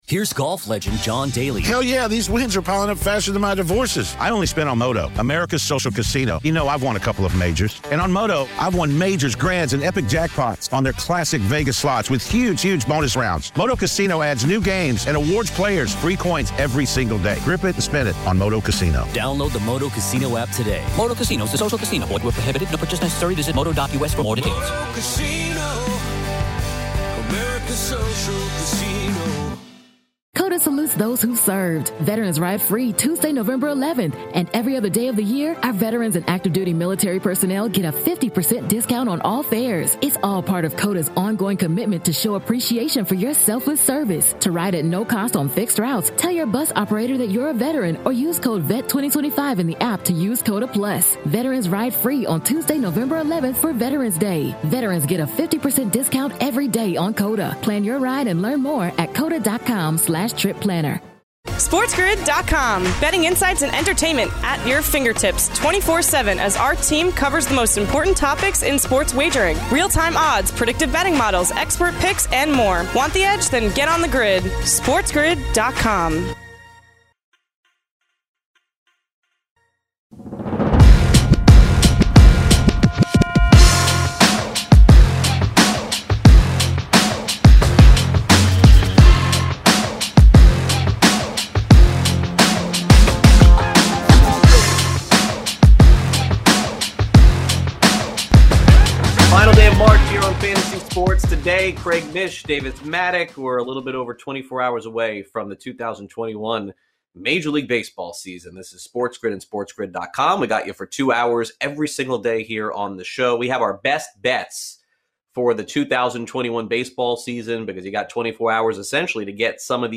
3/31 Hour 1: MLB GM Interviews, MLB Futures, MLB Win Totals/Playoff Odds, & More